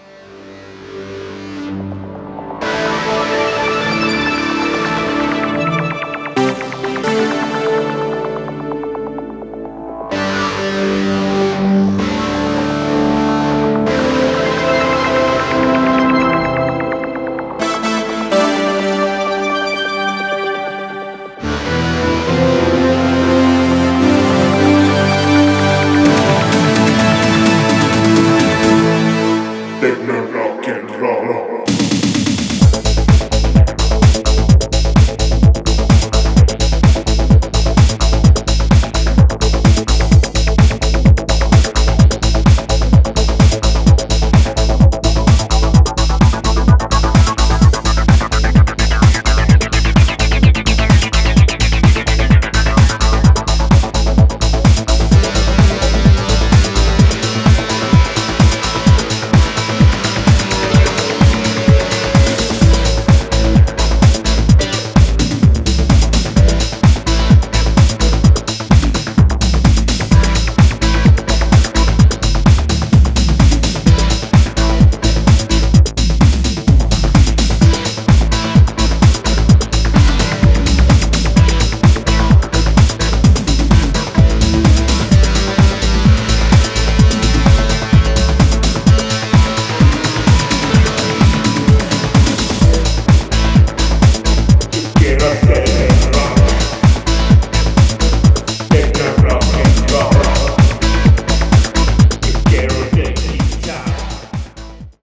荘厳なブレイクからアシッドと硬いグルーヴ、ディストーションの効いたギターリフでヒートアップする